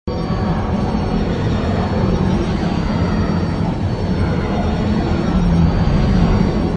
jump_flight.wav